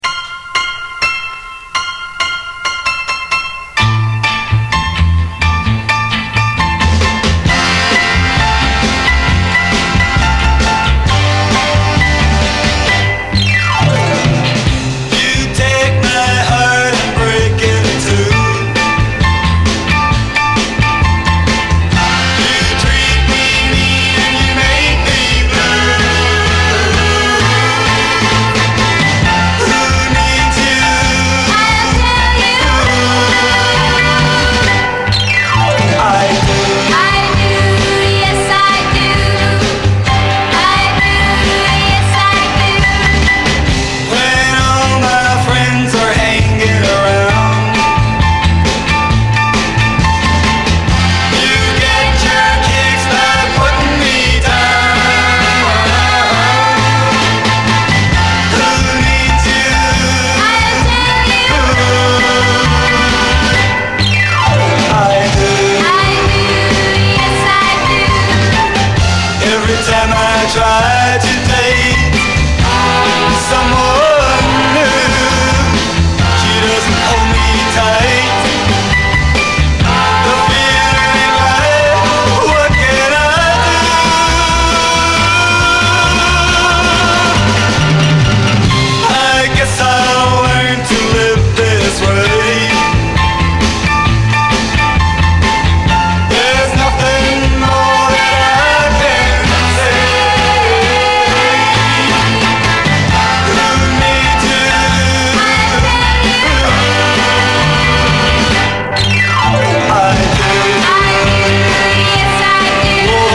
アコギの刻みのイントロから引き込まれる名曲